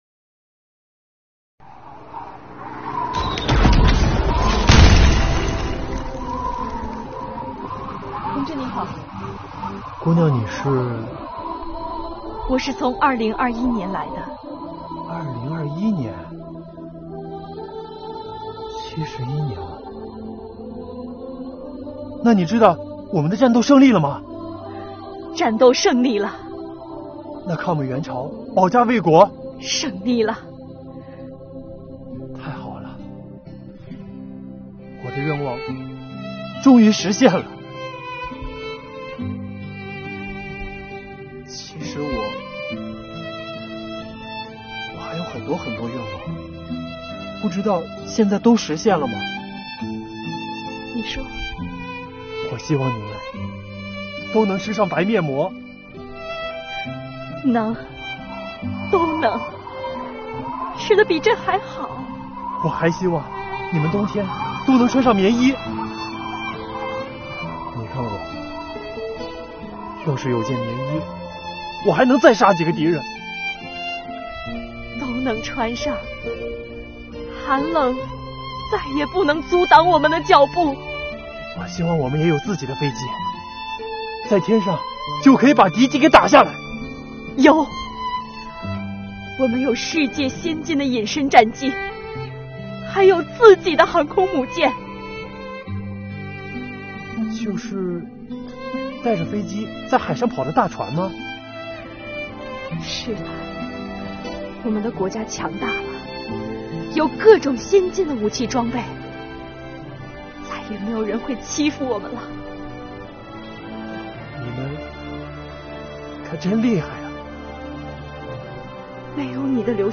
本作品采用时空穿越的形式，通过税务人员与志愿军战士的感人对话，展示了中国人民志愿军不畏强敌、敢于斗争的伟大抗美援朝精神，表达了志愿军战士坚毅质朴的美好心愿，体现了税务人员铭记历史、缅怀先烈的拳拳之心，激发受众饮水思源、不忘革命先烈的爱国主义情怀。